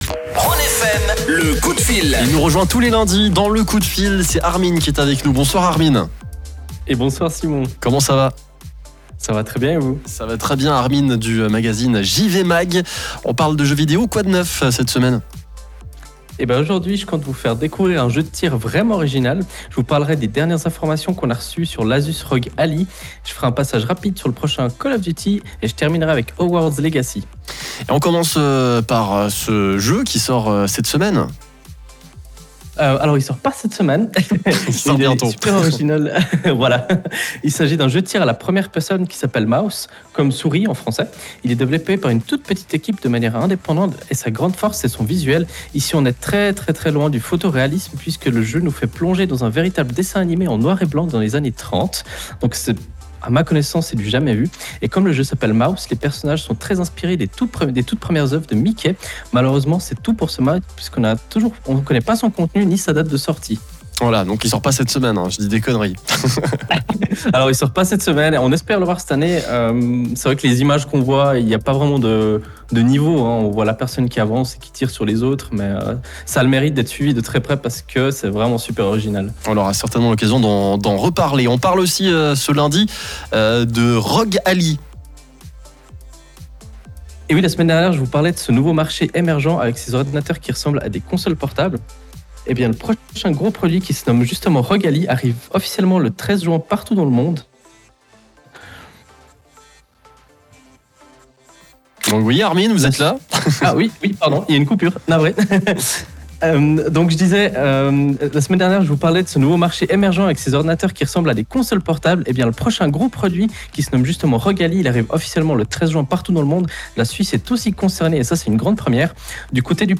Et comme tous les lundis, l’heure de notre chronique gaming a sonné sur la radio Rhône FM.
Vous pouvez réécouter le direct via le flux qui se trouve juste en dessus.